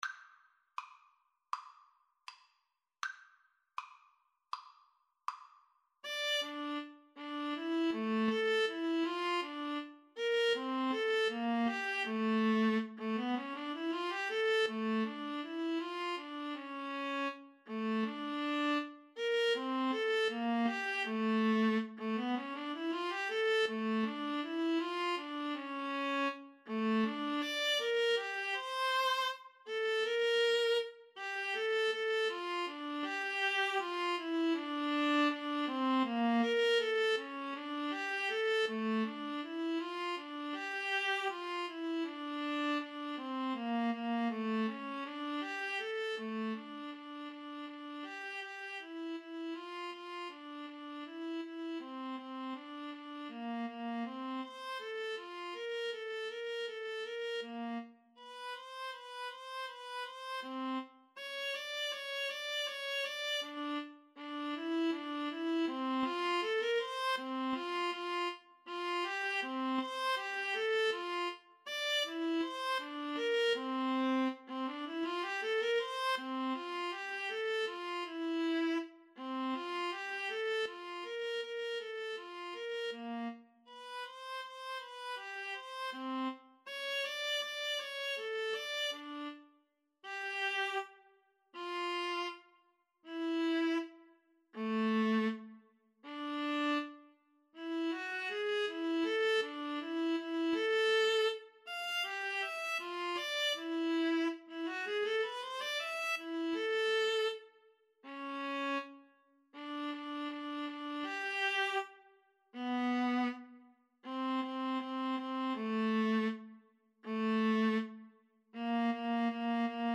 Classical (View more Classical Violin-Viola Duet Music)